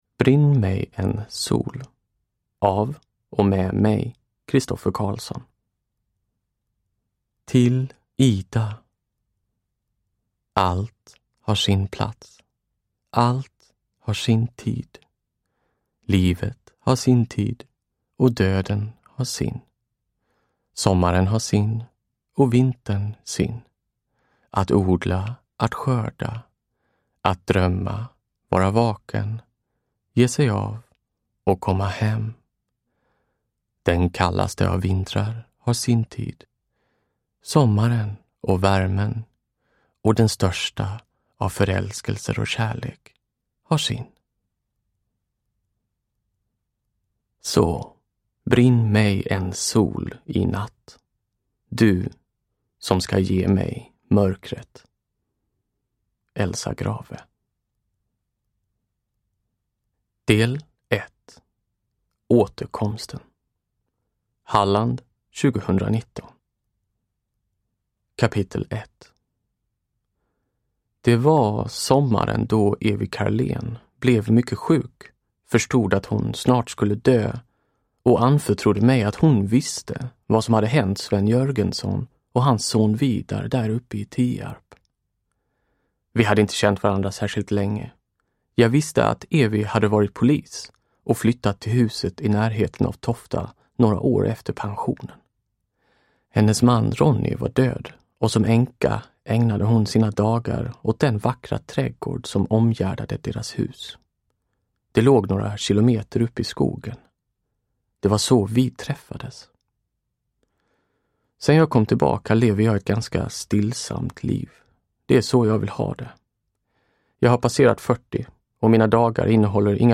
Uppläsare: Christoffer Carlsson
Ljudbok